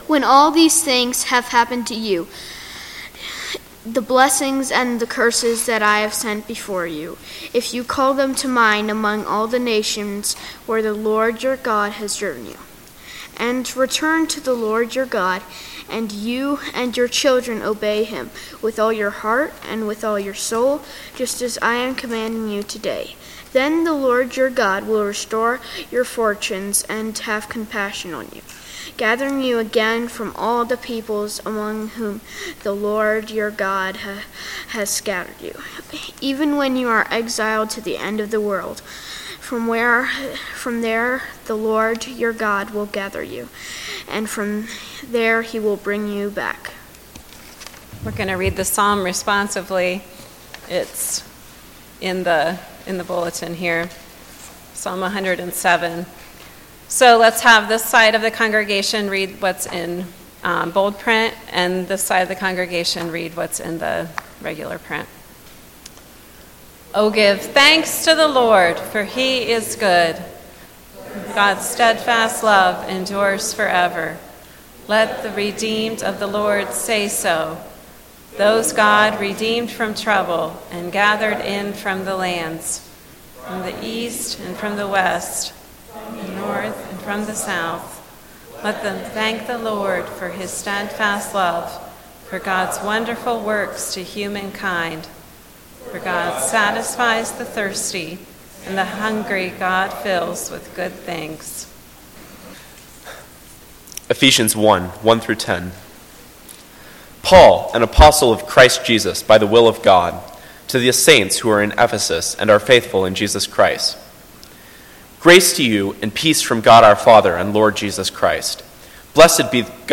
Bible Text: Ephesians 1:1-10 | Preacher